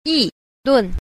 9. 議論 – yìlùn – nghị luận
yi_lun.mp3